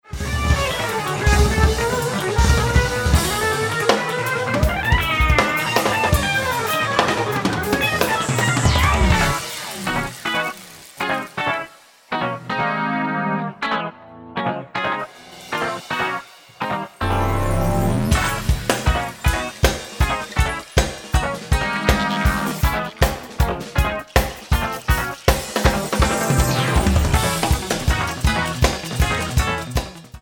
guitar: Agostin Z24